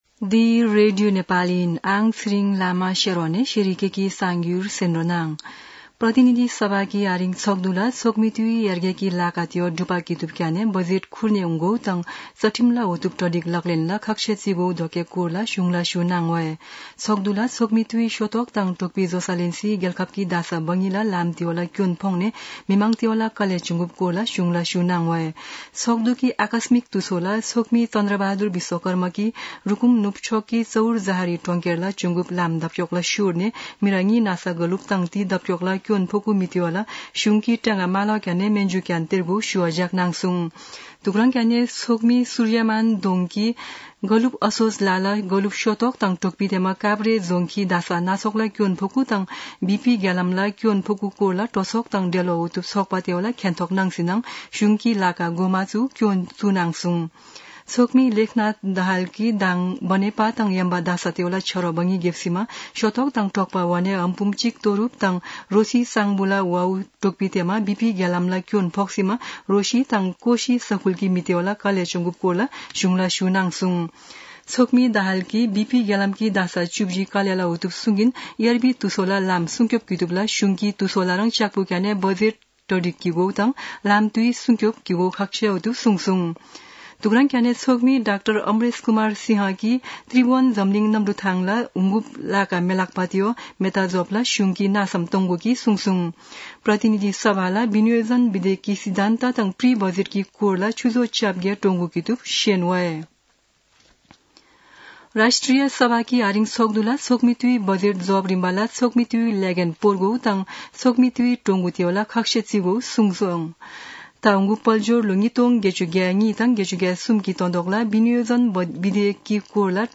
शेर्पा भाषाको समाचार : ३० वैशाख , २०८२
shearpa-news-.mp3